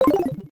adds missing several sound effects
GUI menu close.ogg